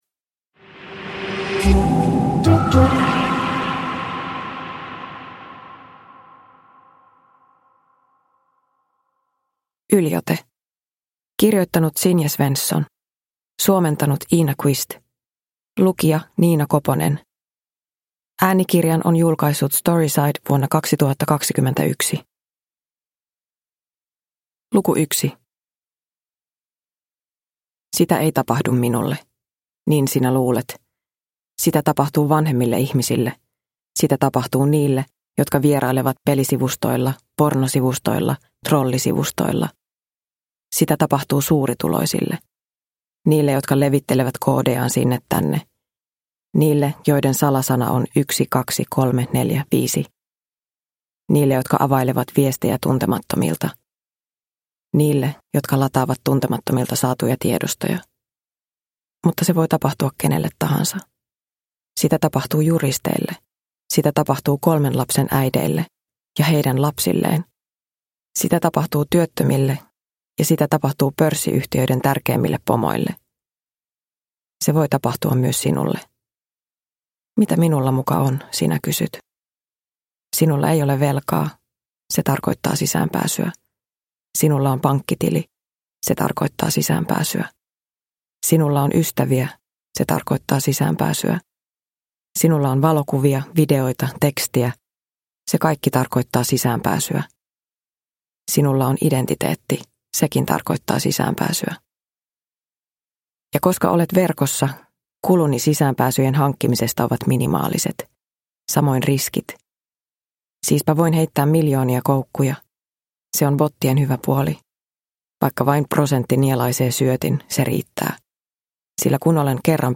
Yliote – Ljudbok – Laddas ner